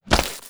SPLAT_Crunch_Crack_02_mono.wav